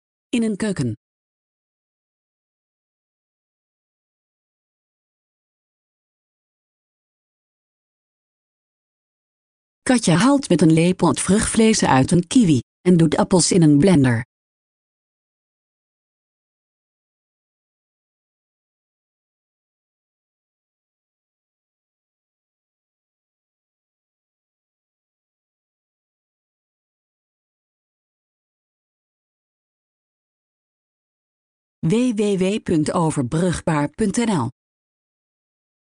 Een vrouw staat in een keuken, ze snijdt appels.